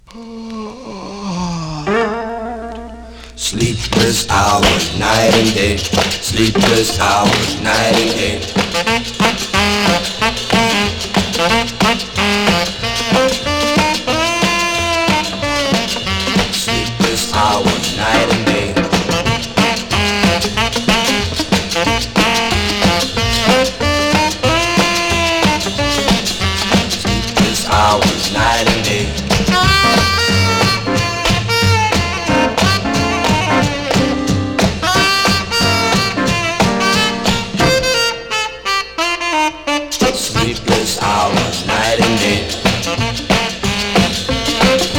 様々なゴリゴリ感が直撃、肉感溢れる音に満たされます。